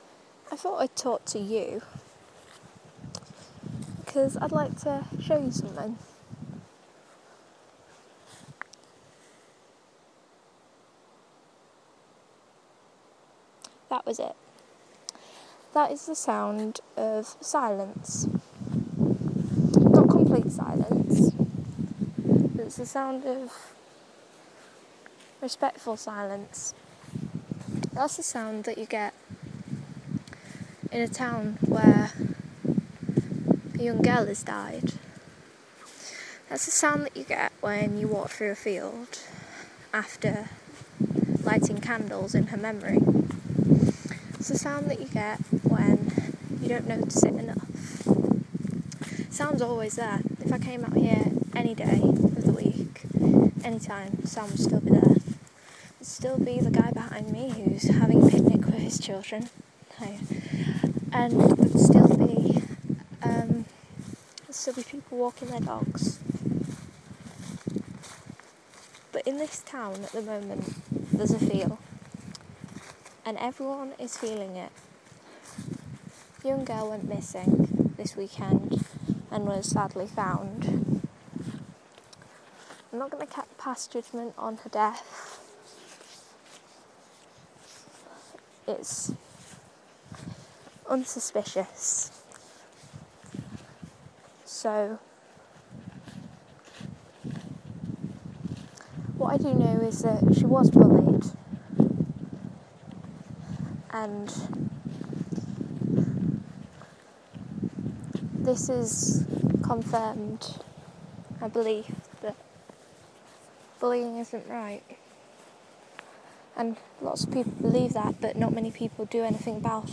Apologies for the not-so-silent wind messing with my mic.